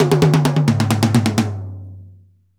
Index of /90_sSampleCDs/Roland L-CDX-01/TOM_Rolls & FX/TOM_Tom Rolls
TOM TOM R02L.wav